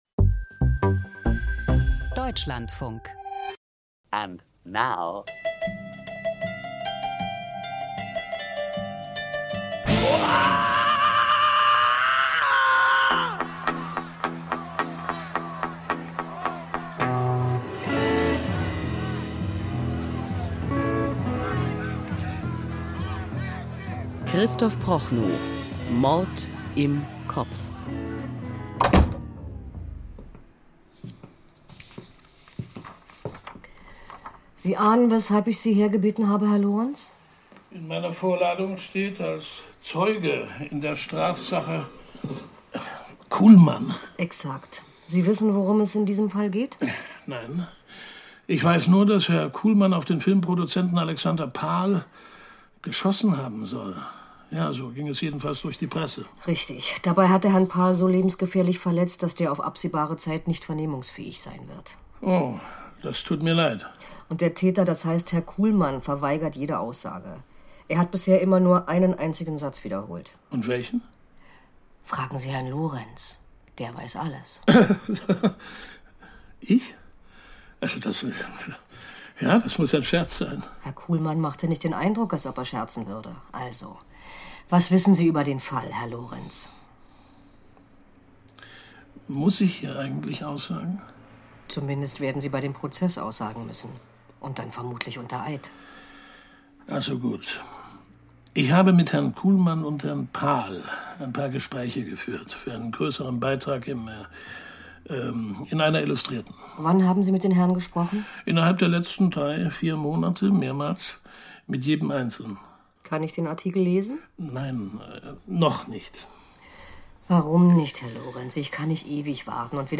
Krimi-Hörspiel: Desertion und Verrat - Mord im Kopf